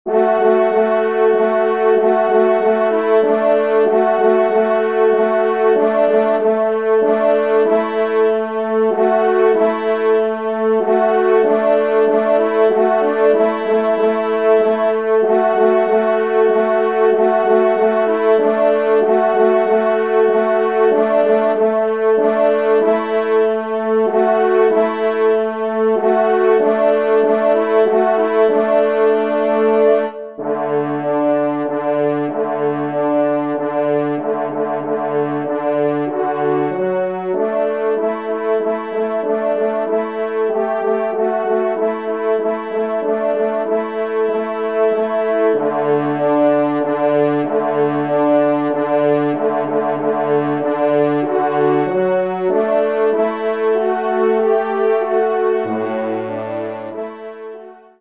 Genre :  Divertissement pour Trompes ou Cors en Ré
3e Trompe